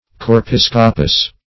Search Result for " chorepiscopus" : The Collaborative International Dictionary of English v.0.48: Chorepiscopus \Cho`re*pis"co*pus\, n.; pl.
chorepiscopus.mp3